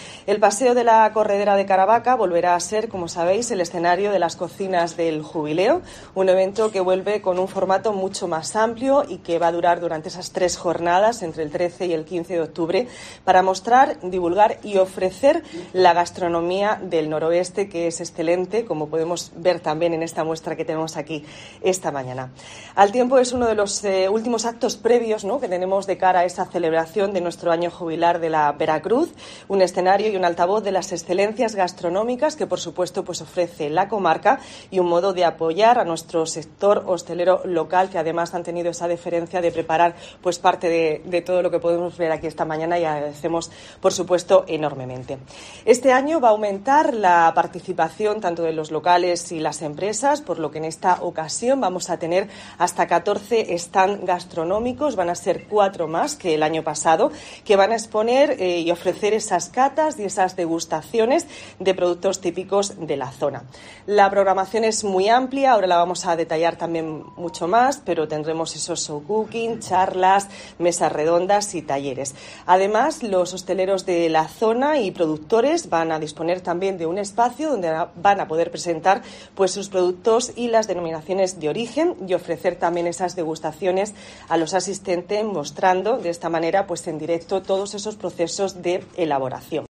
Carmen Conesa, consejera de Cultura, Turismo y Deportes